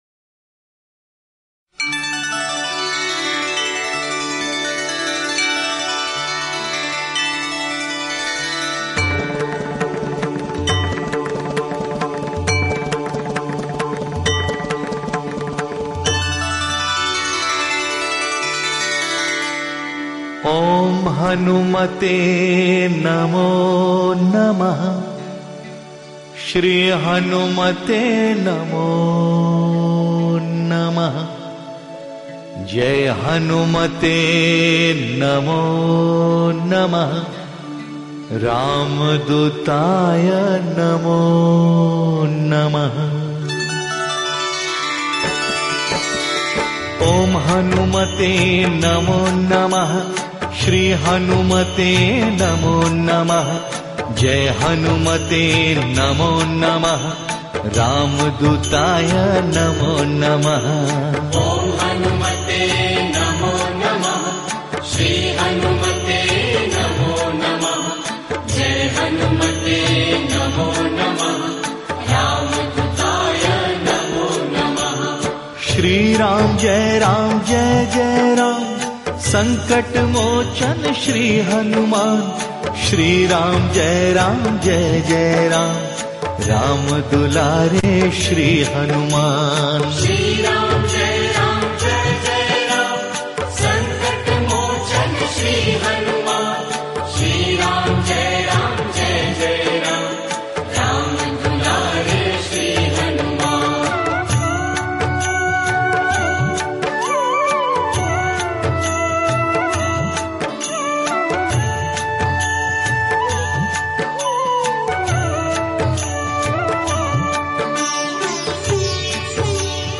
Bhakti Sangeet